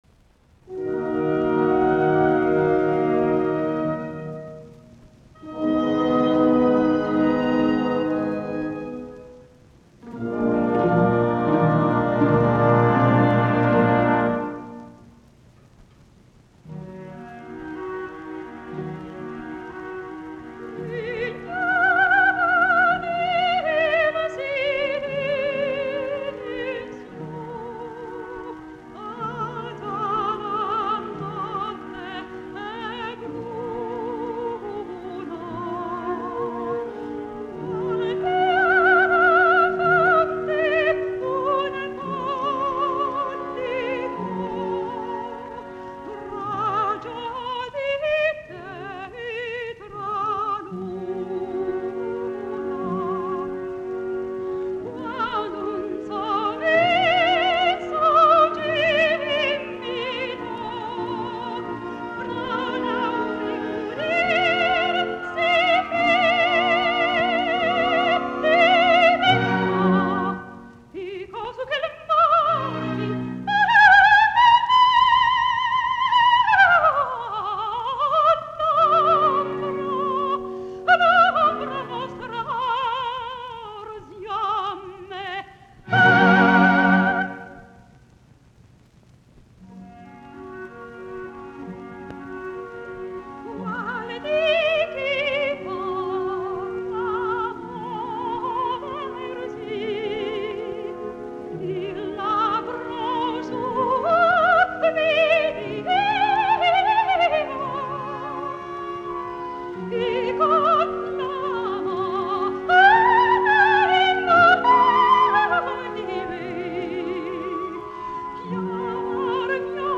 musiikkiäänite
sopraano